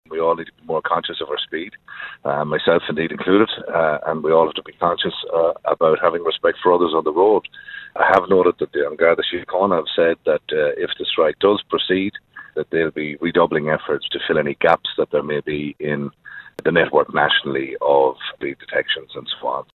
But the Sligo, Leitrim and South Donegal TD says if the strike does go ahead, motorists shouldn’t think they’ll be unpunished if they break the law……………….